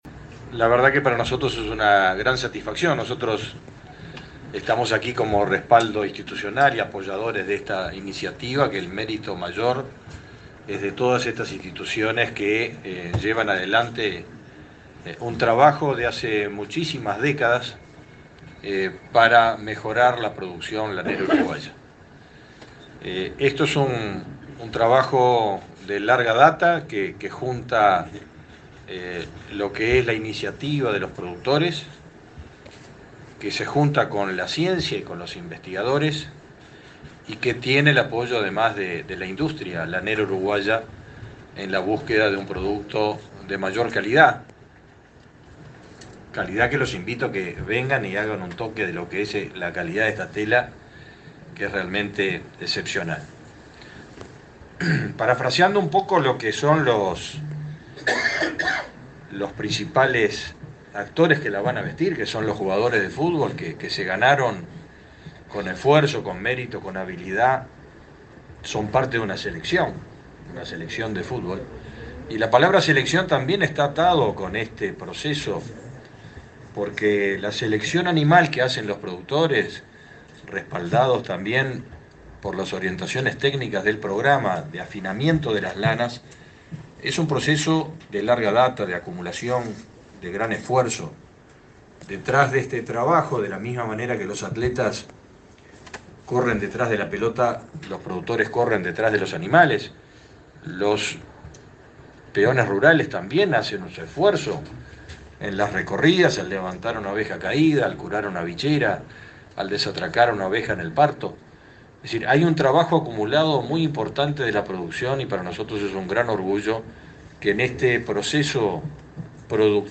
Palabras de autoridades en presentación de proyecto Lana Celeste a Catar
Palabras de autoridades en presentación de proyecto Lana Celeste a Catar 30/08/2022 Compartir Facebook X Copiar enlace WhatsApp LinkedIn El ministro de Ganadería, Fernando Mattos; el secretario del Deporte, Sebastián Bauzá, y el secretario de Presidencia, Álvaro Delgado, participaron en la presentación del proyecto Lana Celeste a Catar, desarrollado por el Gobierno, junto con la Asociación Uruguaya de Fútbol y empresarios de la lana.